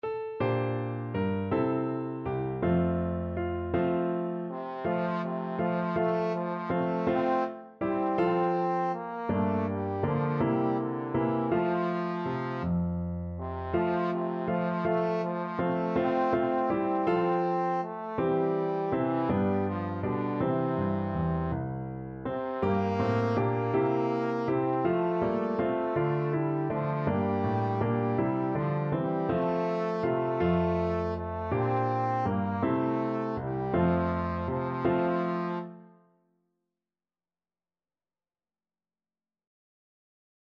Free Sheet music for Trombone
Trombone
F major (Sounding Pitch) (View more F major Music for Trombone )
3/4 (View more 3/4 Music)
One in a bar .=c.54
Traditional (View more Traditional Trombone Music)